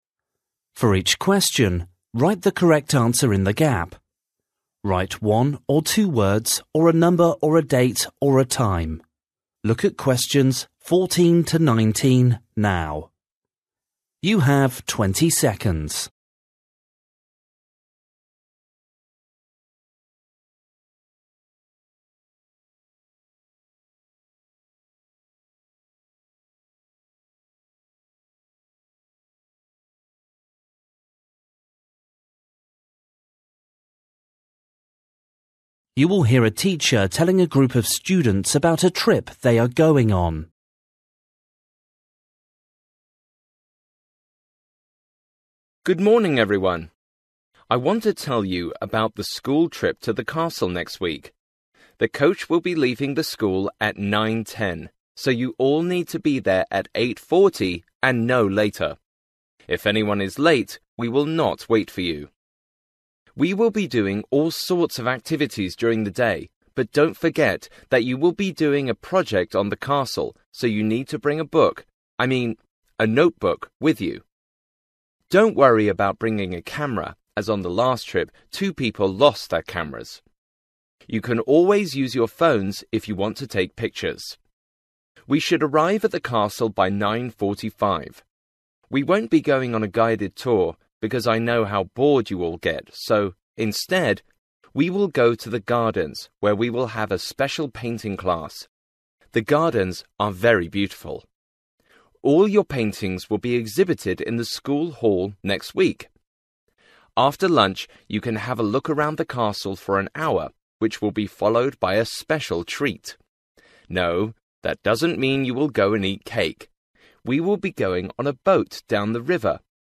You will hear a teacher telling a group of students about a trip they are going on.